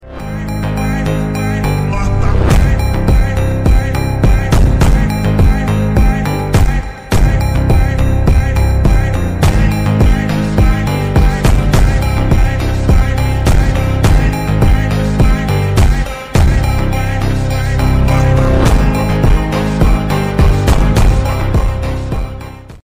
Dodge challenger In a beautiful